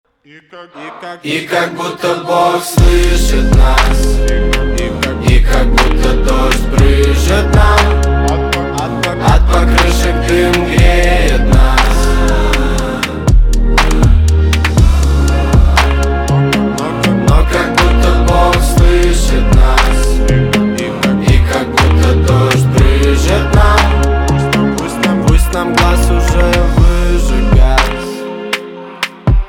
• Качество: 192, Stereo
мужской голос
лирика
грустные
русский рэп
спокойные